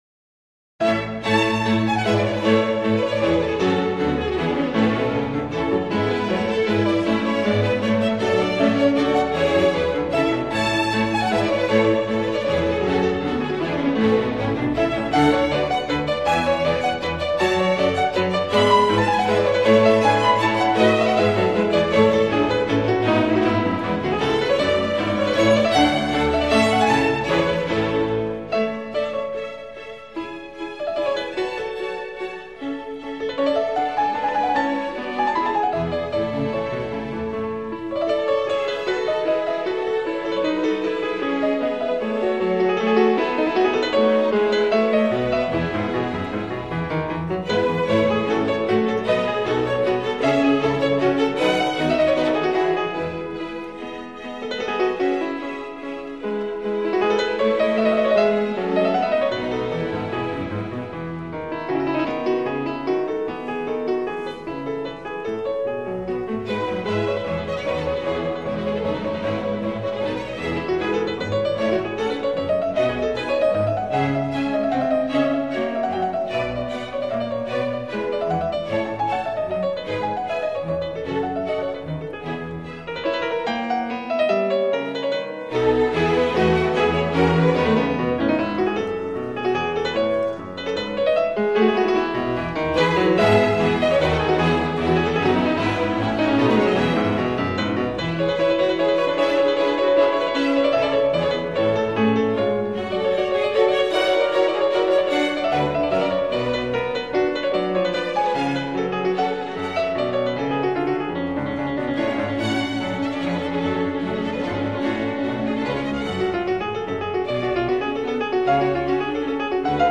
这套全集每张CD都用原版LP转录，并以LP的封面作为CD封面，非常具有收藏价值。
有力、线条分明，充满了旋律的灵动，恰似飞鱼雀跃于音乐的大海之上一样，其中的慢板也弹奏得毫不马虎，犹如宁静夏夜的星空一样